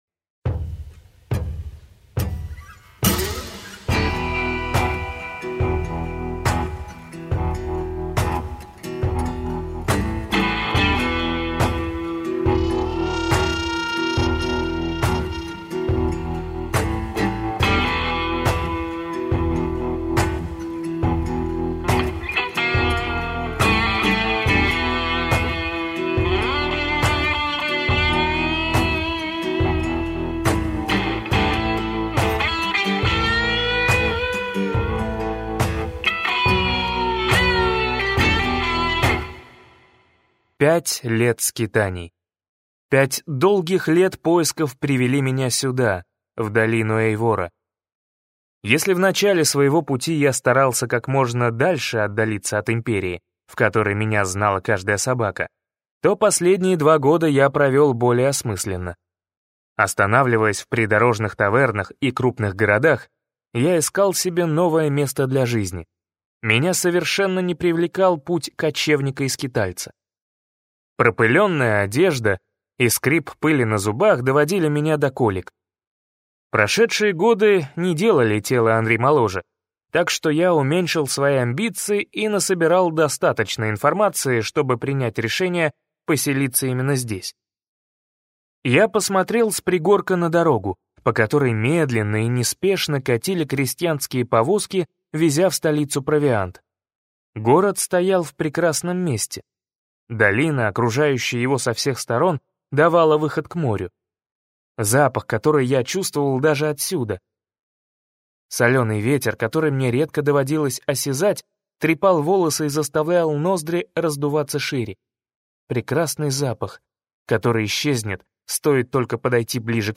Аудиокнига Возрождение - купить, скачать и слушать онлайн | КнигоПоиск